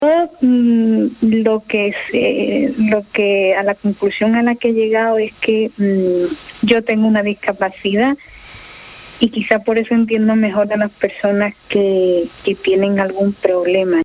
Musicalidad en su habla, relajada, desvela lentamente un espíritu inquieto, divertido, colaborador.
Una entrevista